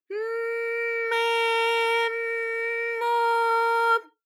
ALYS-DB-001-JPN - First Japanese UTAU vocal library of ALYS.
m_m_me_m_mo.wav